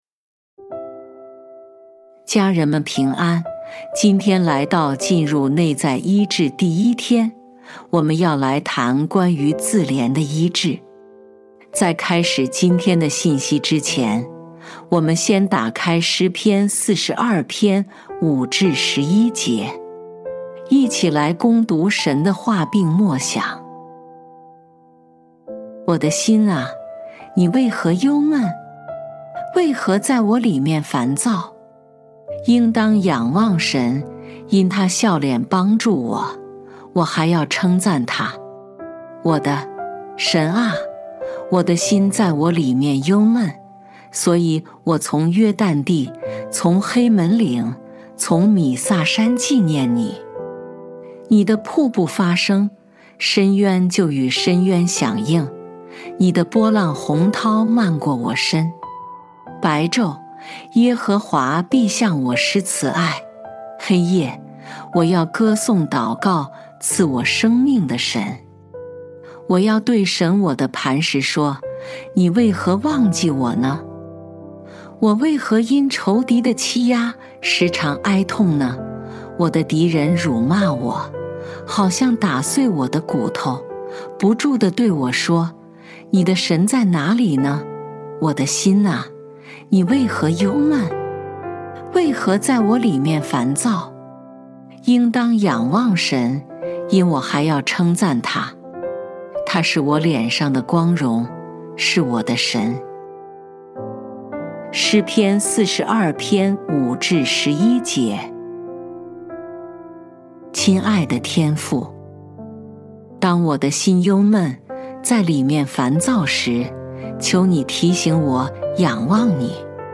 本篇是由微牧之歌撰稿祷告及朗读